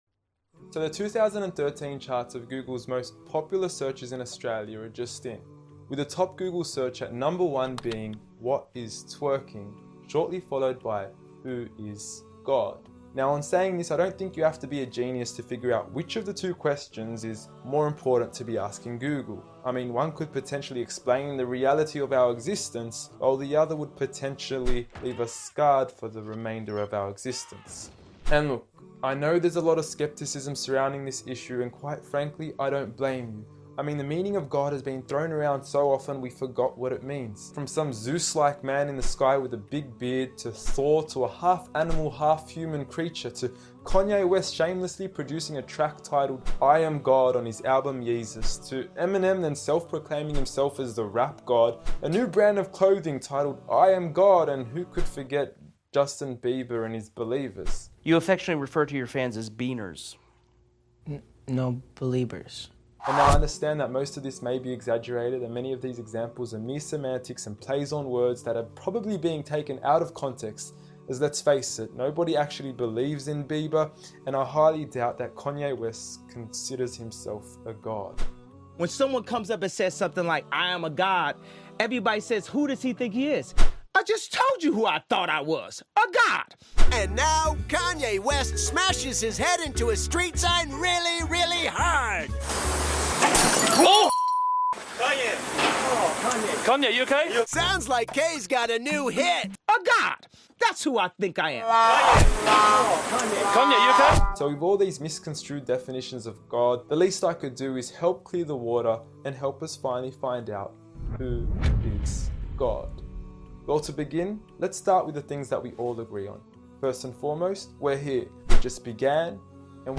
*No Music was used in the production of this video